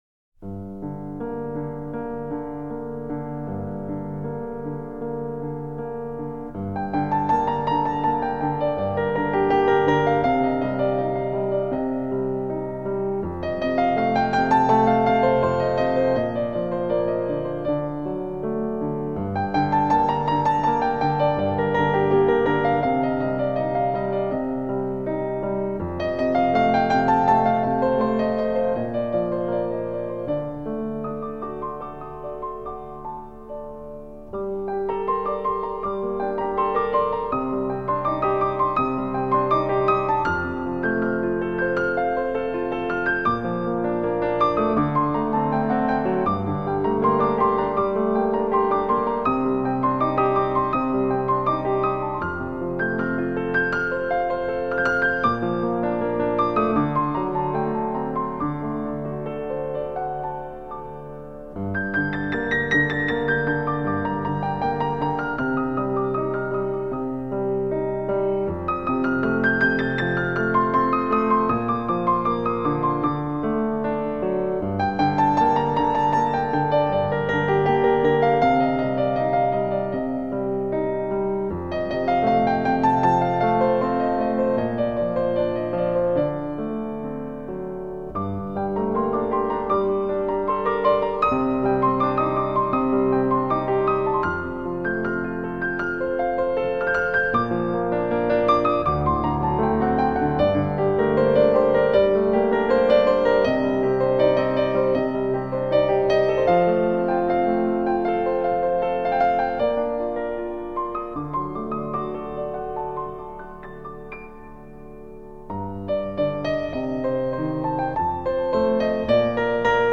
• Качество: высокое